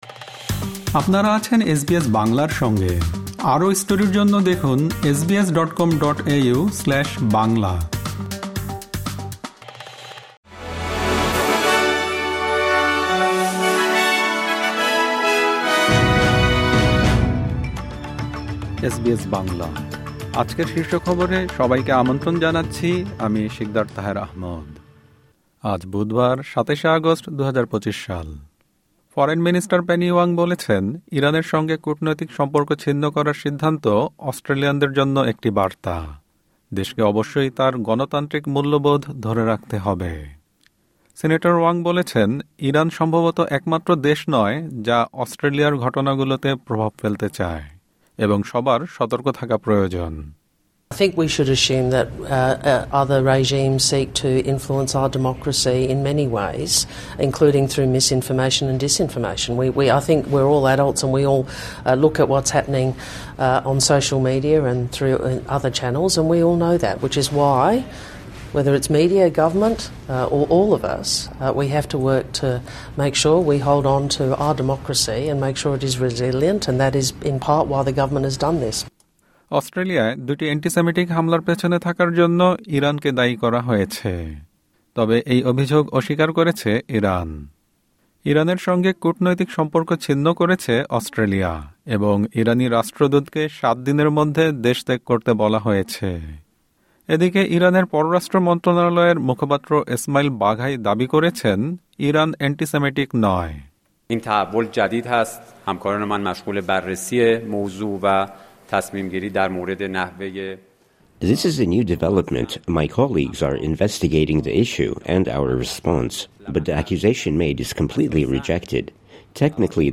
এসবিএস বাংলা শীর্ষ খবর: ২৭ আগস্ট, ২০২৫